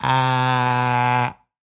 Звуки высовывания языка
Звук, когда человек показывает язык врачу и произносит А